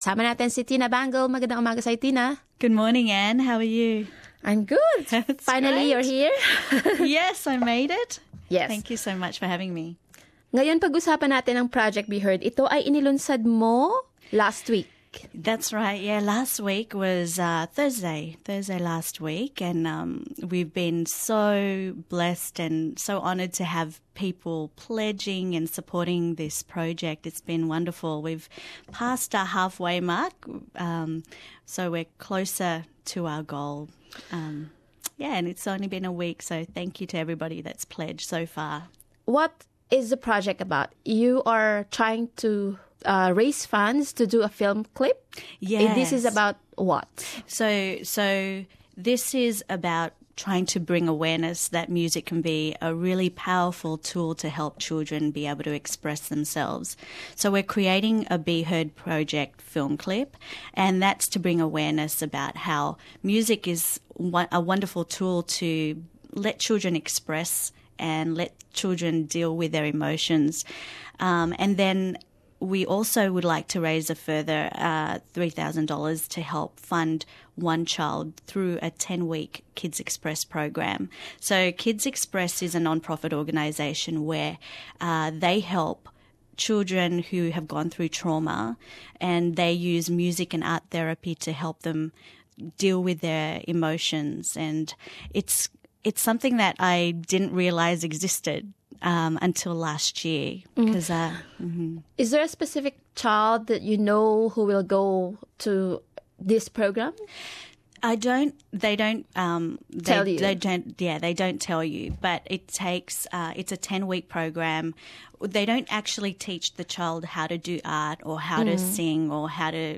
at SBS studios in Sydney